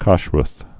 (käshrəth, -rəs, käsh-rt)